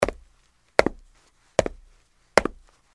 走-硬地面-高跟鞋.mp3
通用动作/01人物/01移动状态/01硬地面/走-硬地面-高跟鞋.mp3